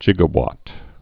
(gĭgə-wŏt, jĭg-)